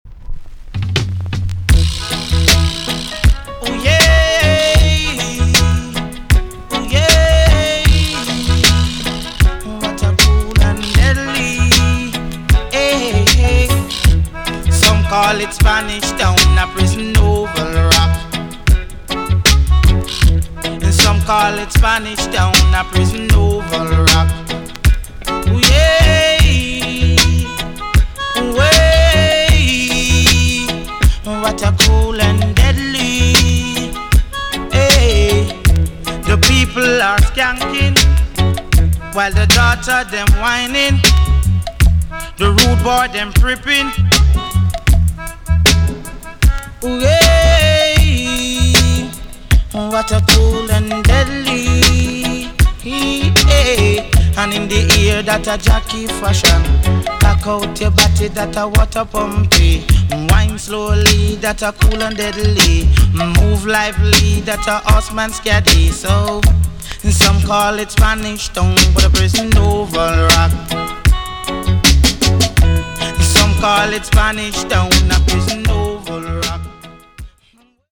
TOP >LP >80'S 90'S DANCEHALL
A.SIDE EX 音はキレイです。